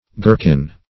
girkin - definition of girkin - synonyms, pronunciation, spelling from Free Dictionary Search Result for " girkin" : The Collaborative International Dictionary of English v.0.48: Girkin \Gir"kin\, n. [Obs.]